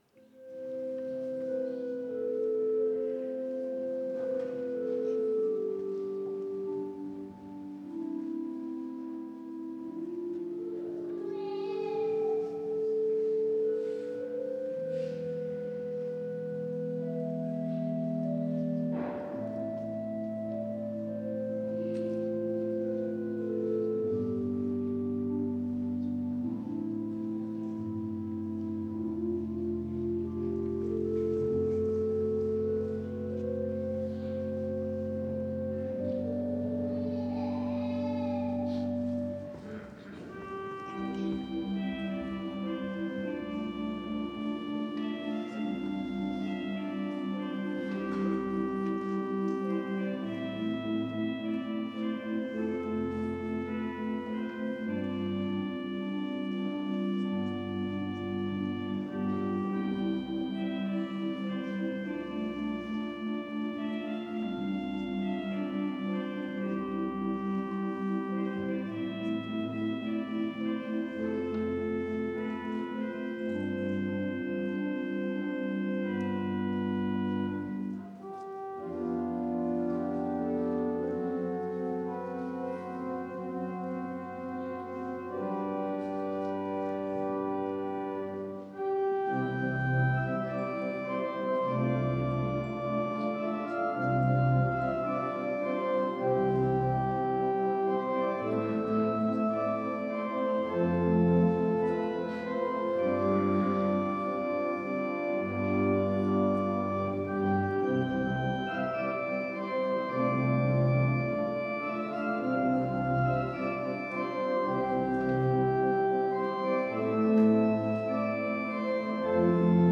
13. Orgelstück zum Ausgang
Audiomitschnitt unseres Gottesdienstes vom Letzten Sonntag nach Epipanias 2026.